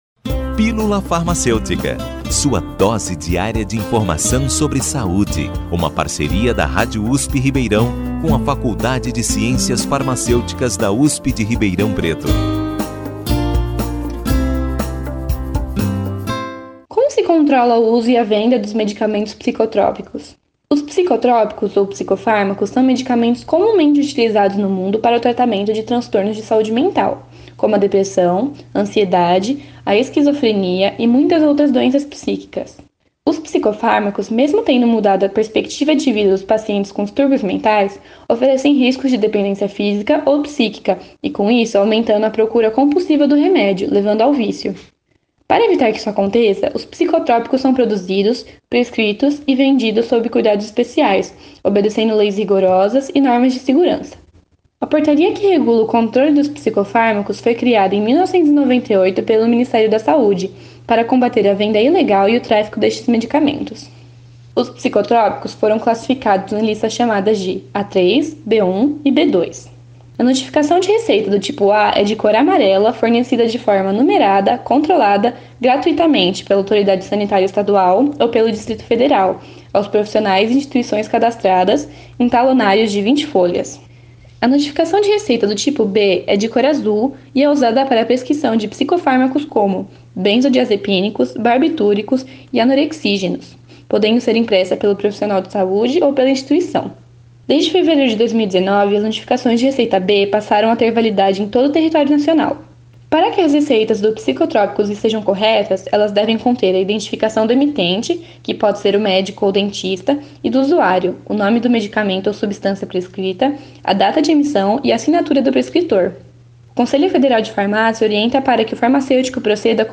O boletim Pílula Farmacêutica é apresentado pelos alunos de graduação da Faculdade de Ciências Farmacêuticas de Ribeirão Preto (FCFRP) da USP